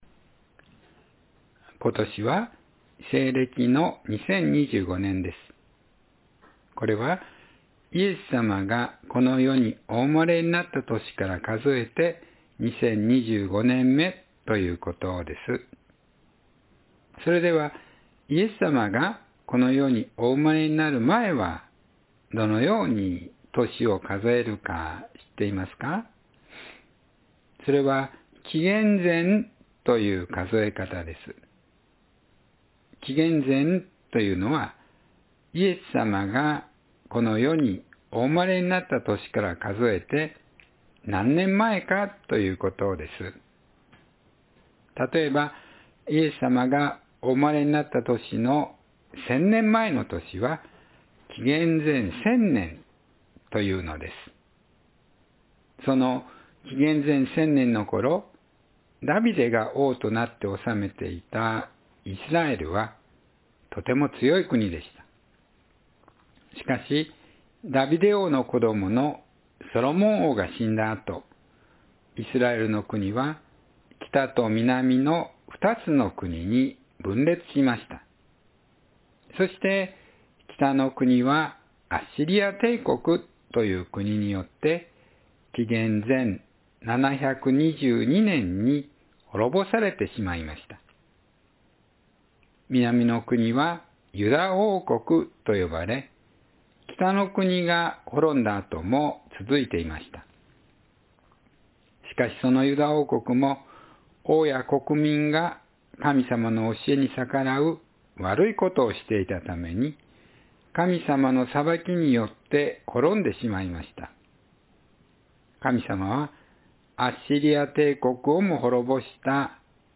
権威をゆだねられたエレミヤ“Jeremiah Given Authority over Nations and Kingdoms”（2025年2月16日・子ども説教） – 日本キリスト教会 志木北教会